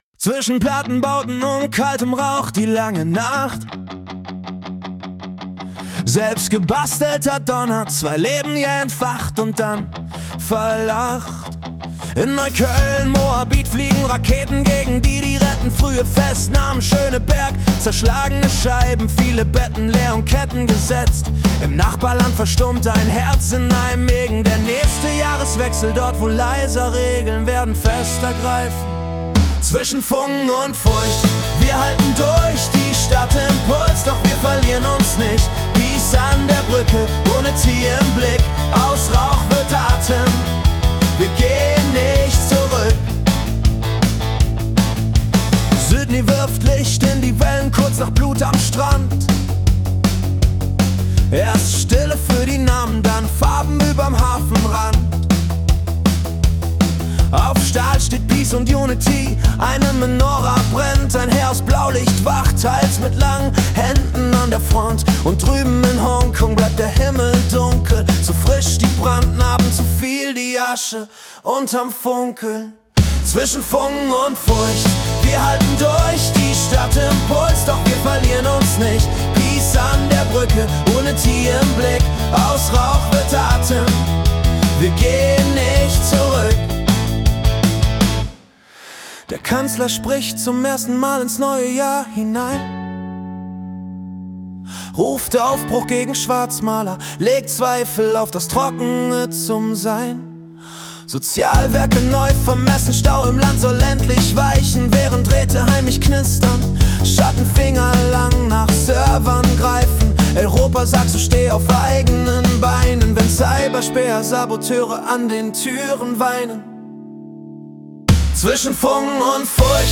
Die Nachrichten vom 1. Januar 2026 als Rock-Song interpretiert.
Erlebe die Geschichten der Welt mit fetzigen Riffs und kraftvollen Texten, die Journalismus...